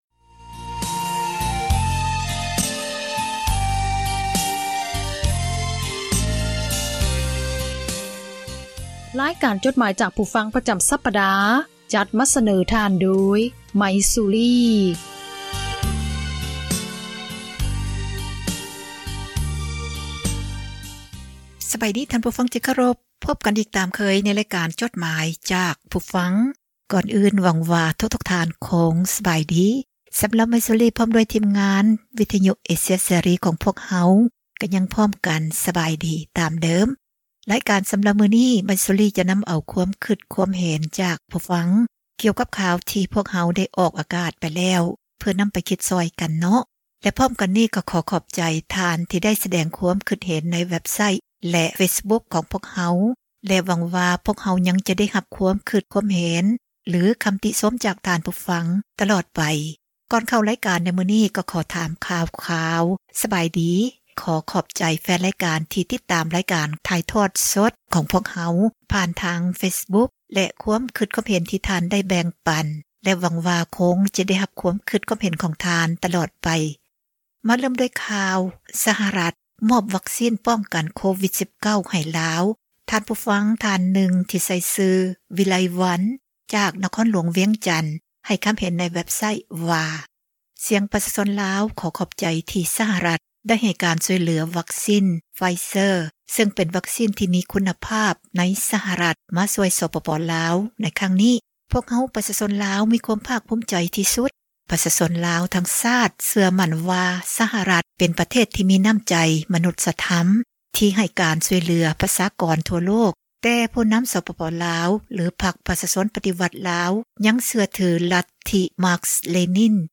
ອ່ານຈົດໝາຍ, ຄວາມຄຶດຄວາມເຫັນ ຂອງທ່ານ ສູ່ກັນຟັງ ເພື່ອເຜີຍແຜ່ ທັສນະ, ແນວຄິດ ທີ່ສ້າງສັນ, ແບ່ງປັນ ຄວາມຮູ້ ສູ່ກັນຟັງ.